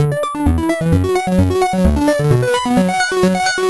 Just For Fun D 130.wav